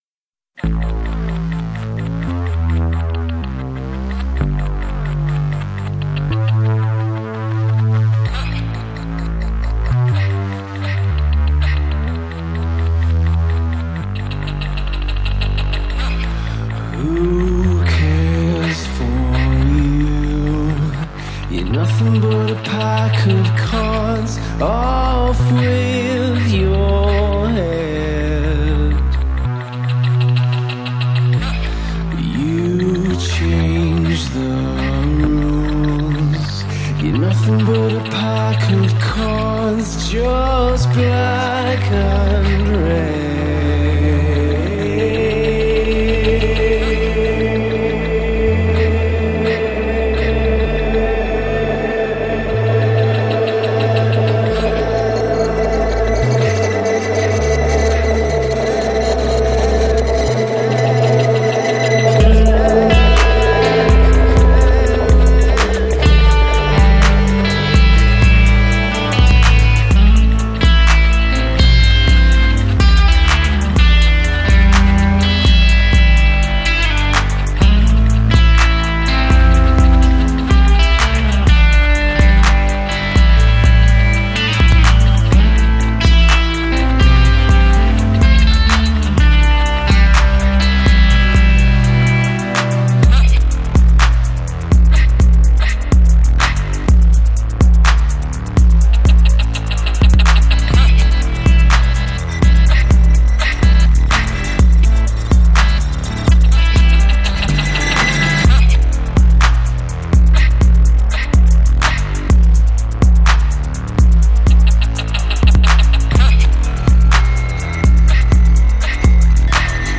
Guitar/Electronic two-piece
vocals/guitar
Ableton Push, laptop, iPad